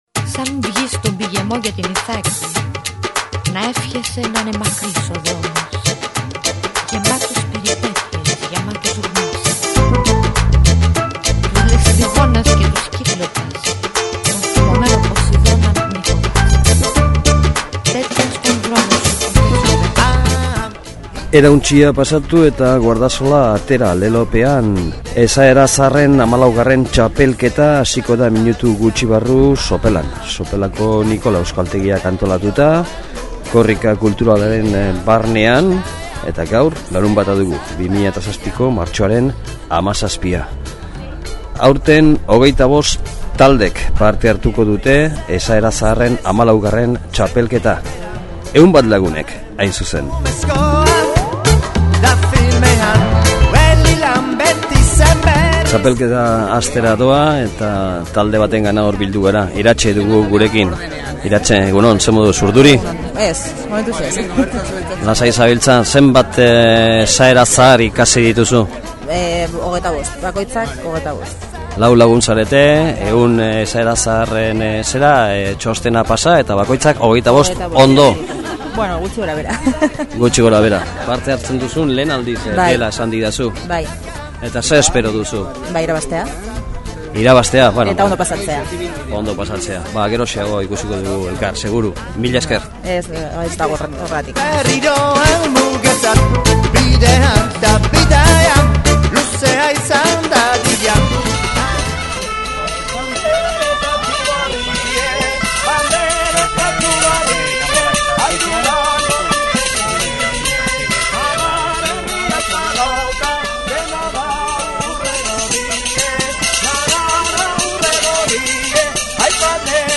ERREPORTAJEA: Sopelako Esaera Zaharren XIV. Lehiaketa
Bi zatitan banatzen da lehiaketa, bietan bertsolarien ezinbesteko laguntzarekin.
Taberna bakoitzean bertsolariak bertso jarria bota eta, txandaka, taldeek asmatu behar dute aldez aurretik ikasitako 100 esaerako zerrendatik zein esaerari egiten dion aipamena bertsoak.